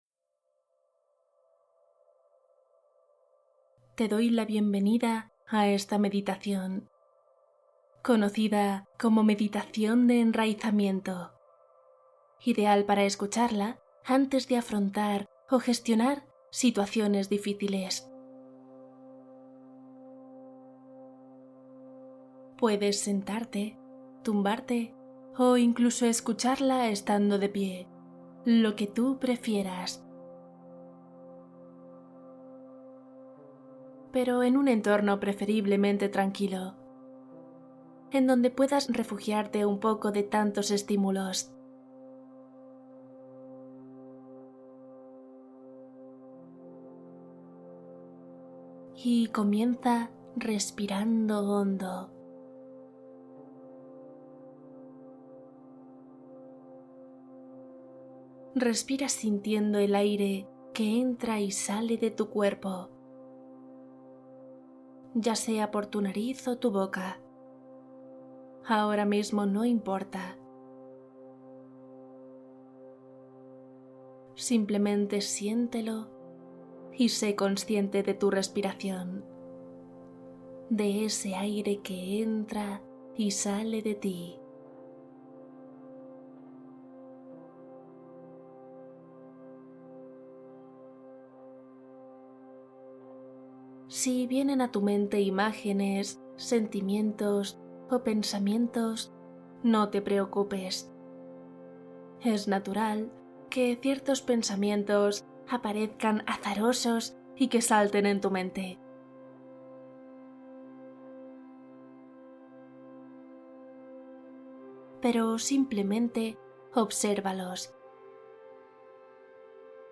Meditación para afrontar momentos difíciles y dormir con éxito interior